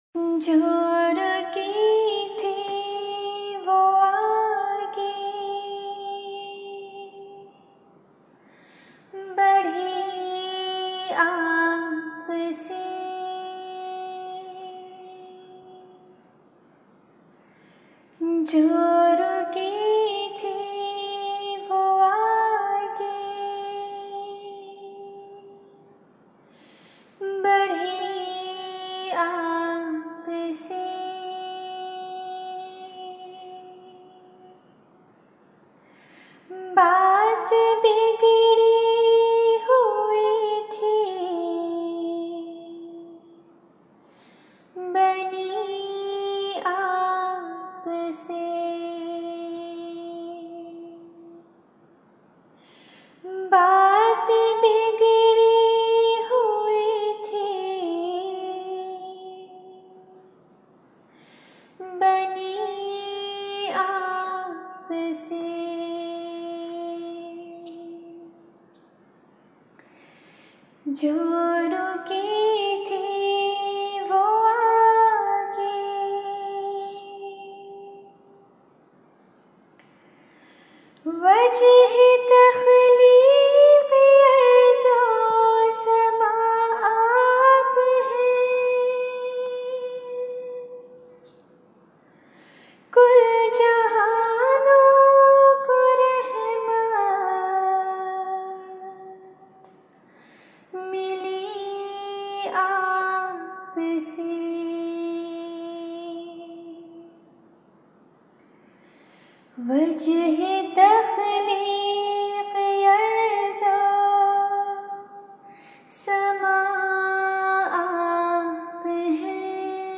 آواز: ممبر لجنہ اماءاللہ Voice: Member Lajna Ima`illah